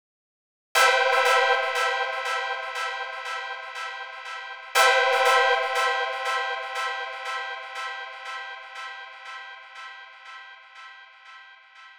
23 Pad PT2+2 extra bars.wav